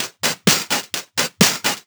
Index of /VEE/VEE Electro Loops 128 BPM
VEE Electro Loop 300.wav